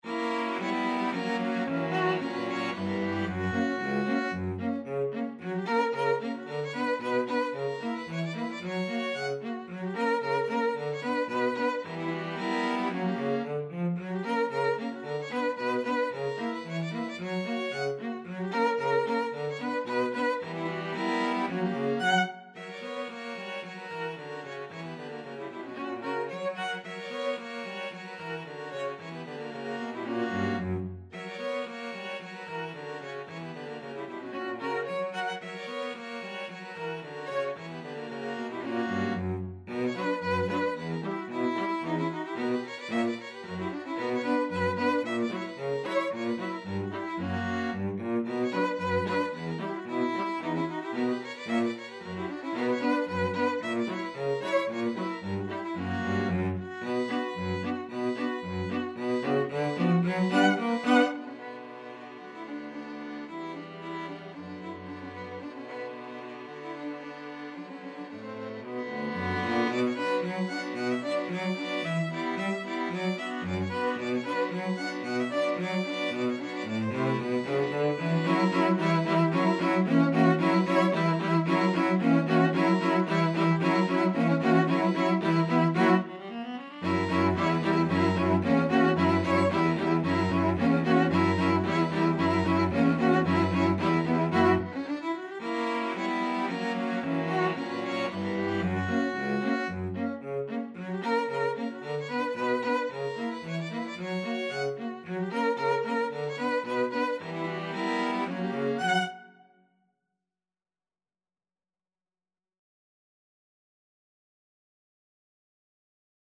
Traditional Trad. Unser Toirele String Quartet version
Violin 1Violin 2ViolaCello
B minor (Sounding Pitch) (View more B minor Music for String Quartet )
2/4 (View more 2/4 Music)
Traditional (View more Traditional String Quartet Music)
world (View more world String Quartet Music)